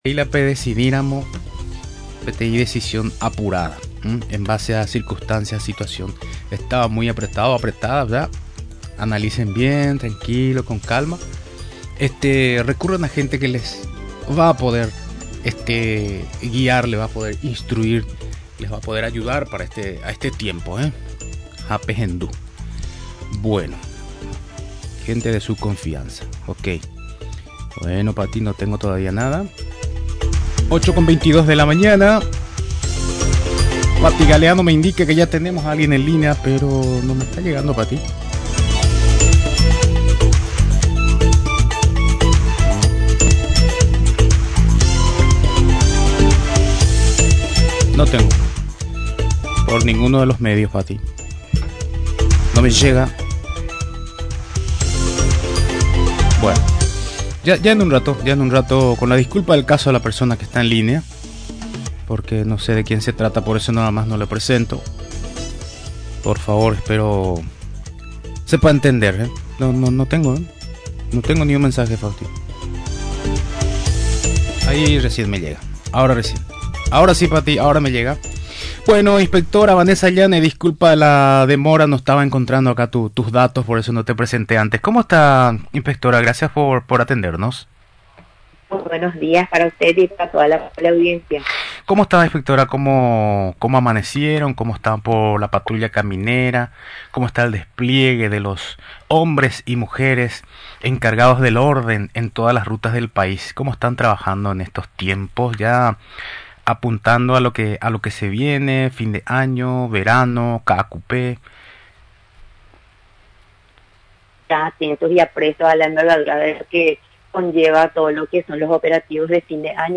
Finalmente, aseguró durante la entrevista en Radio Nacional del Paraguay, que este procedimiento continuara el próximo jueves, en otro punto del país.